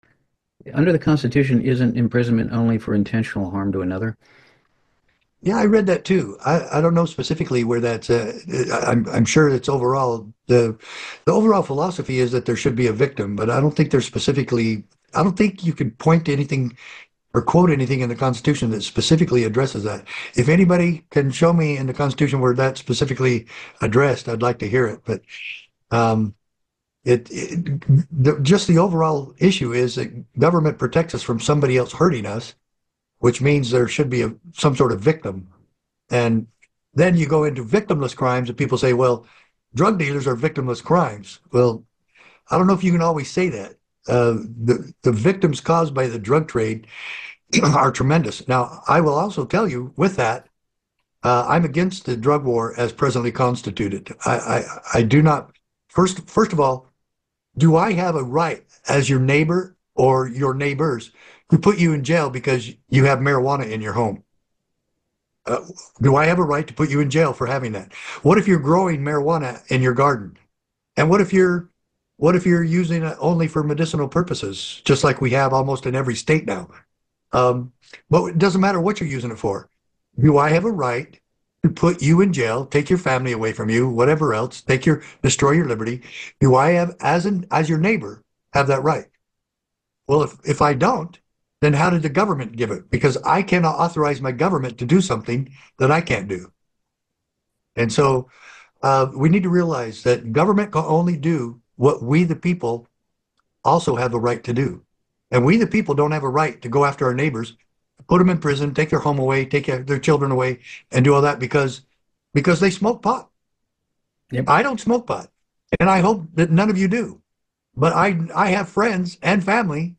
The speaker concludes by expressing appreciation for the support of Sheriff Eberly and others in their community.